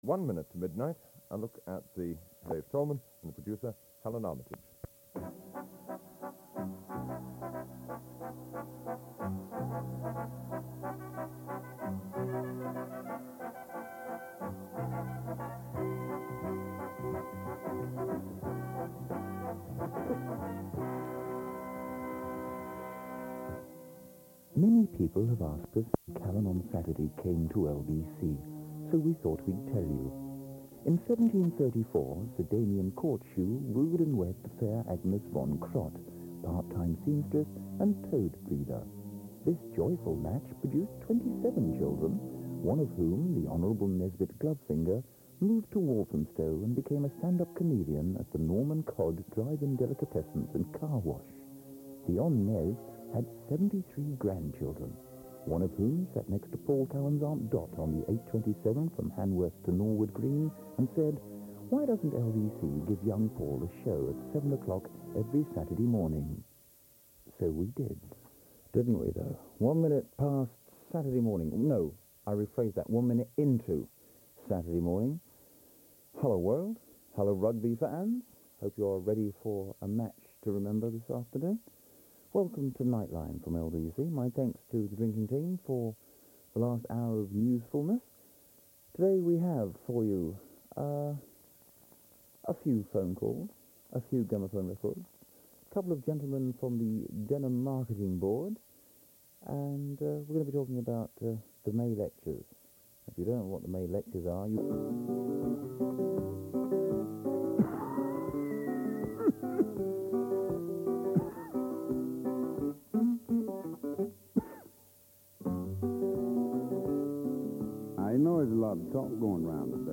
The following are, for the most part, unedited and taken straight from the cassettes recorded off air. My aim was to get either jingles, voices or ads and there is some irritating switching on and off of the tape in the recordings.